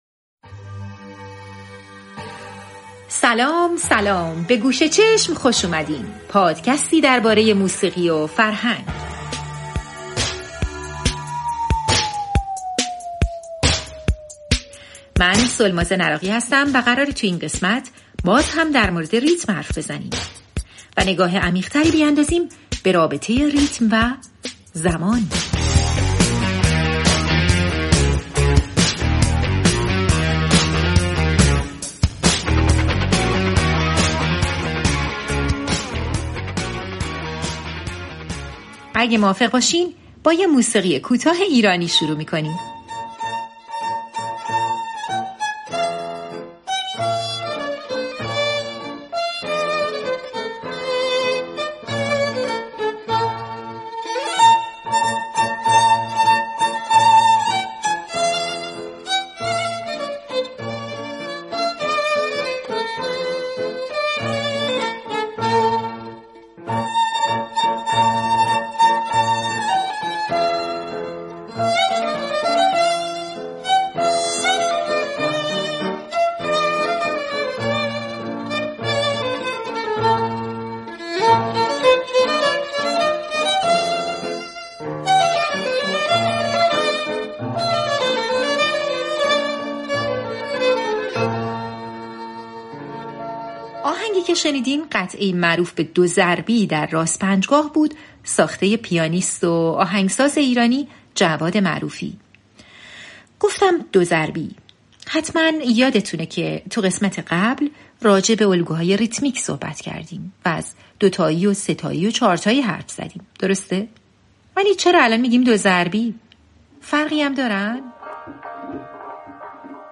در این قسمت از گوشچشم ضمن گوش کردن به آثار درخشان تاریخ موسیقی، نگاهی می‌اندازیم به رابطه ریتم و زمان